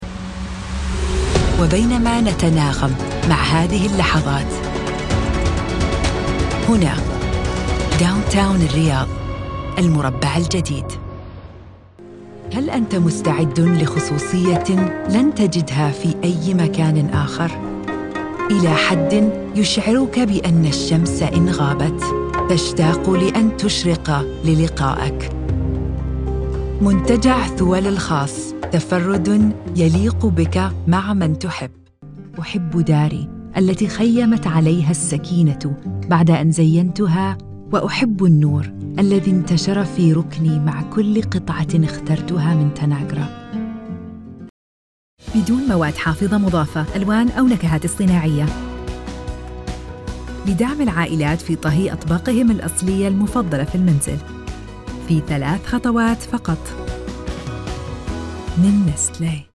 Tief, Natürlich, Unverwechselbar
Erklärvideo